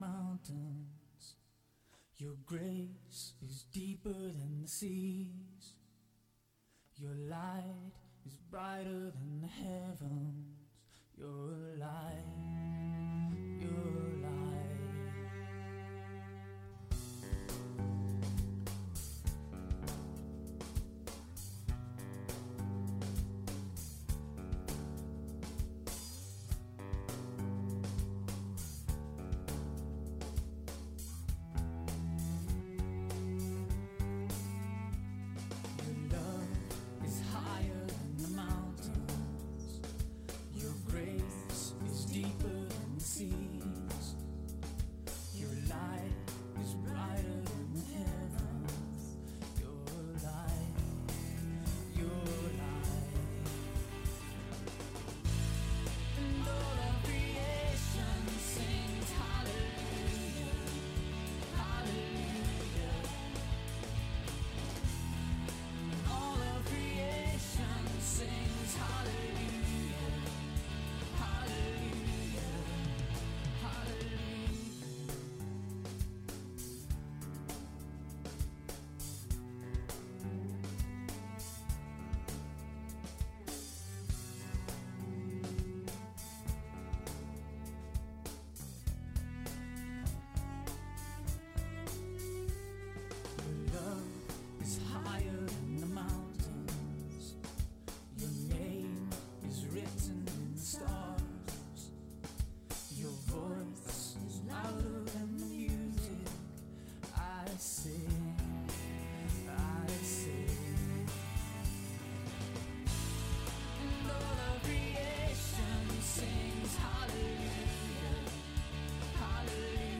December 24 Worship Audio – Full Service